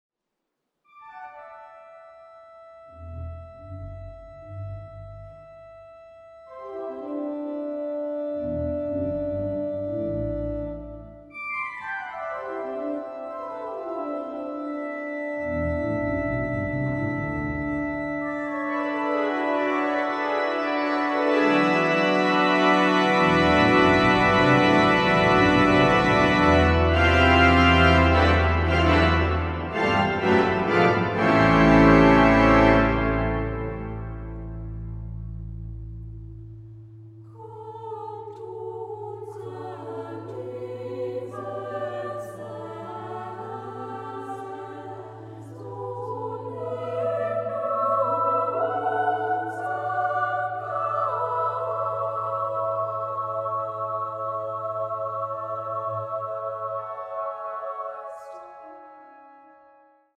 Works for Women’s and Children’s Choir and Solo Songs